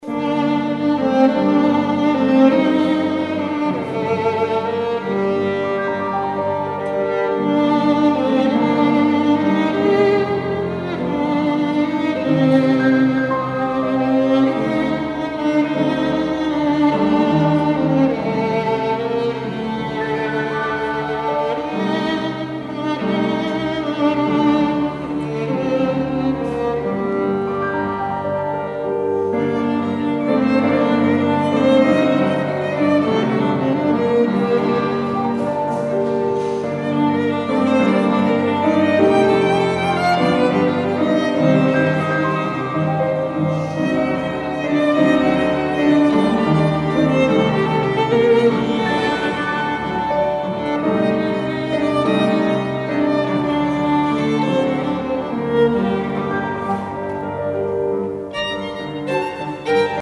This is the live record. Sorry for some ambiance noises.